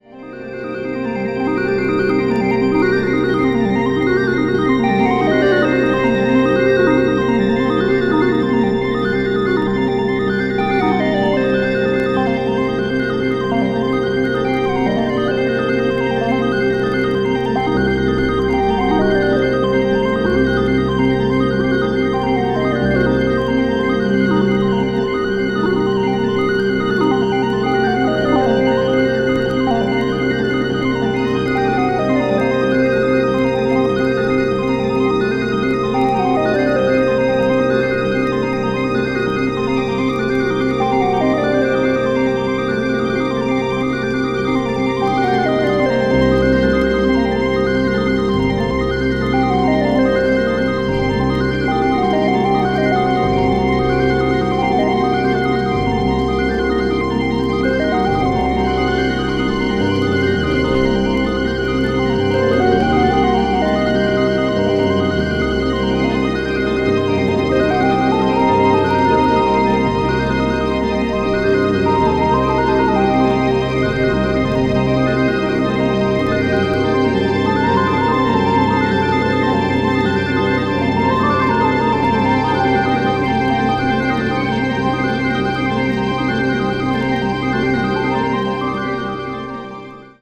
media : EX-/EX-(some light noises.)